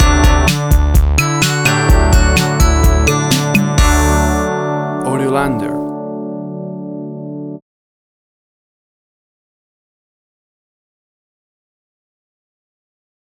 A cool explosion of classic 80s synth music!
WAV Sample Rate: 16-Bit stereo, 44.1 kHz
Tempo (BPM): 128